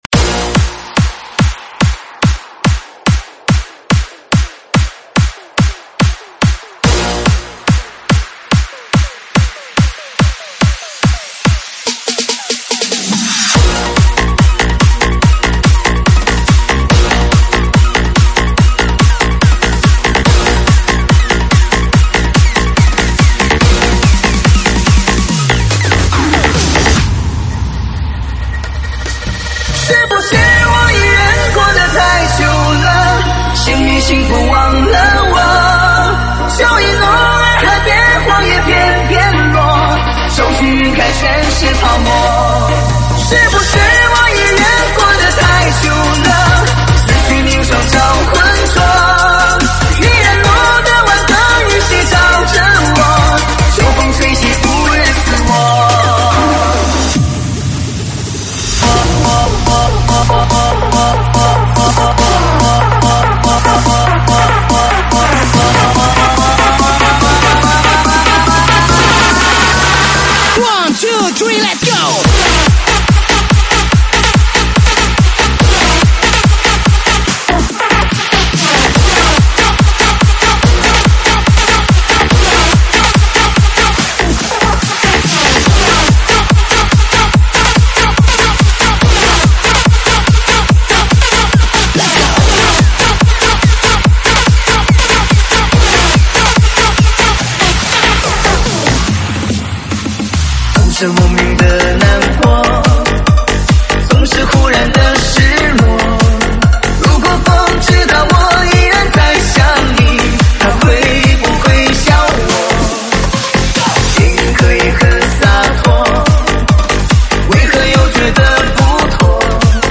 舞曲类别：中文舞曲